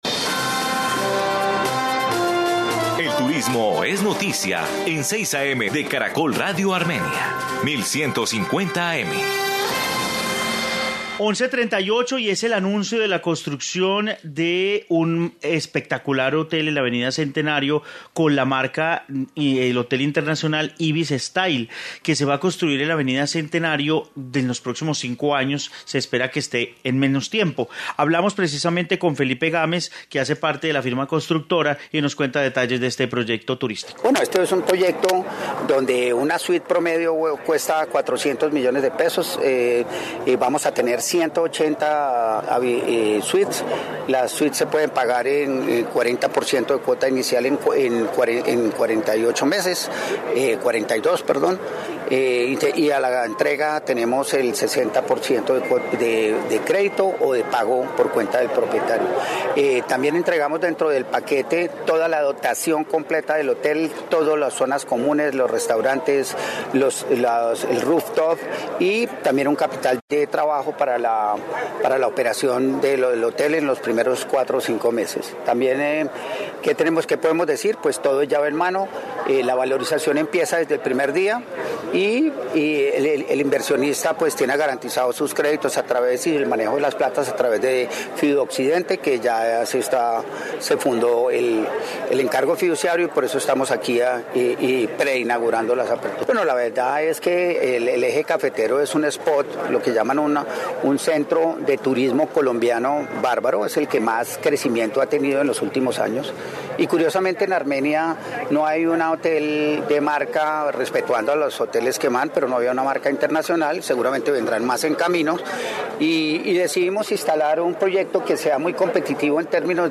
Informe nuevo hotel en Armenia